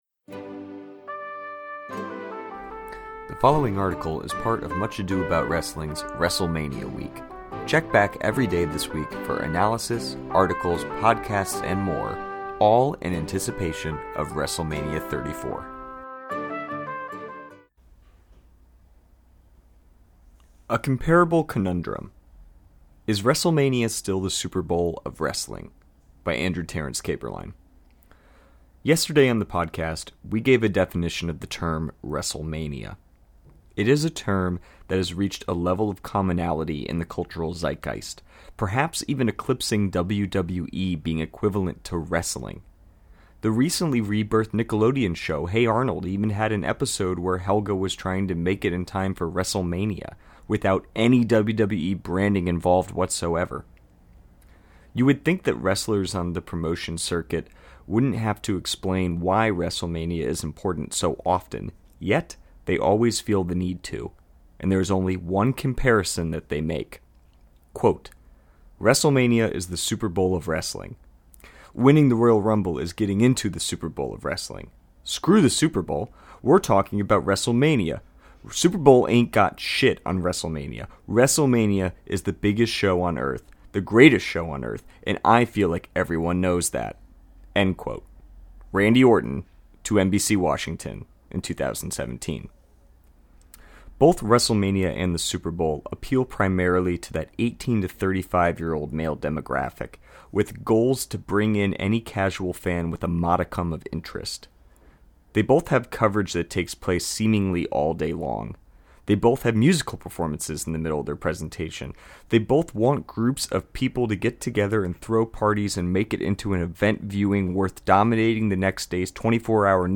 Want this article read to you?